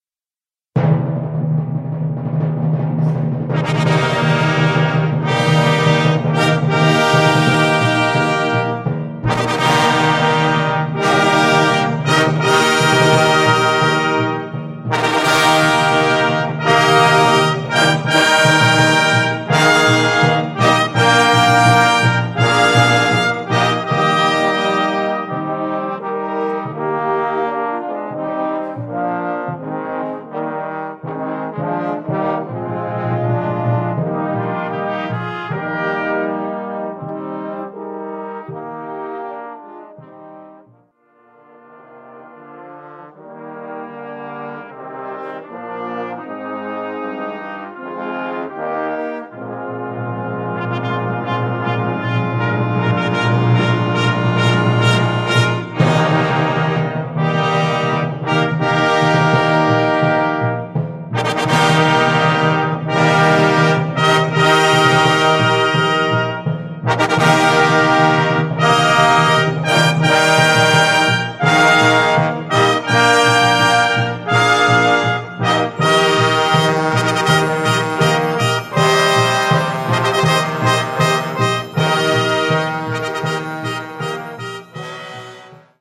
Besetzung: Blasorchester
mitreißenden Fanfare
• 2 Trompeten
• 1 Horn
• 2 Posaunen
• 2 Pauken
• 1 Becken
• 1 Triangel